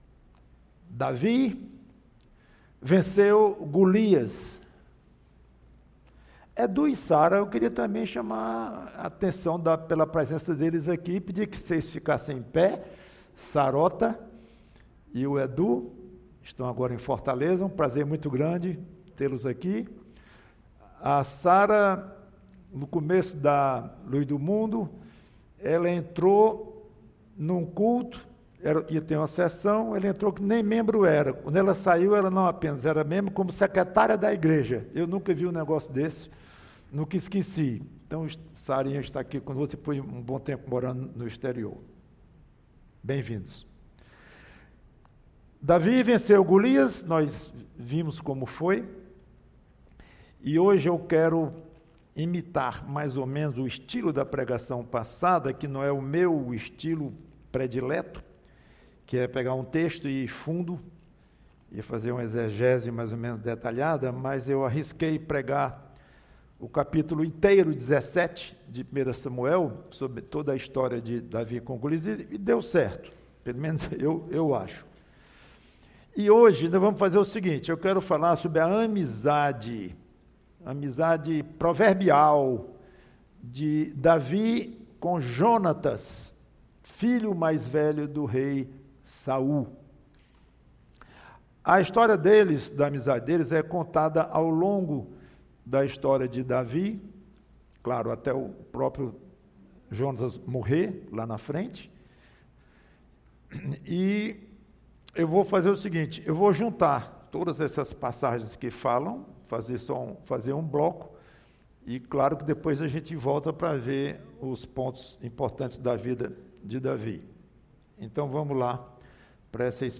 PREGAÇÃO